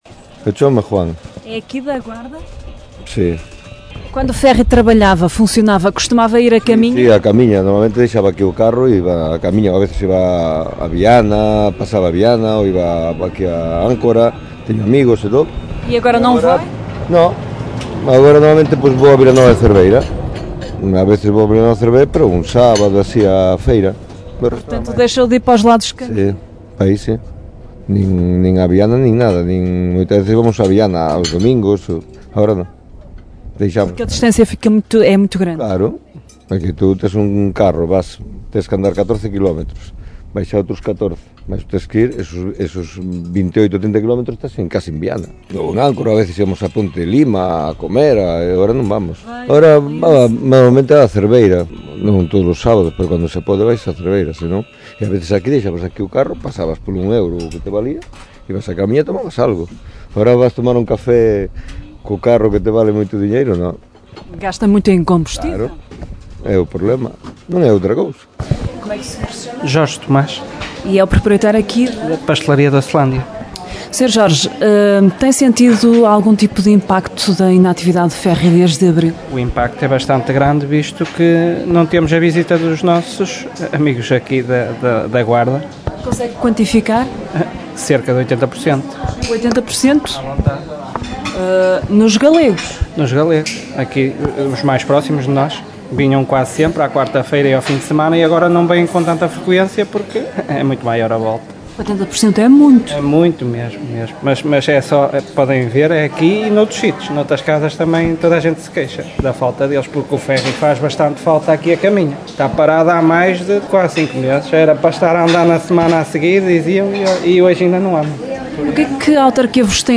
A Rádio Caminha saiu à rua e ouviu habitantes de Caminha e La Guardia e empresários dos dois lados da fronteira.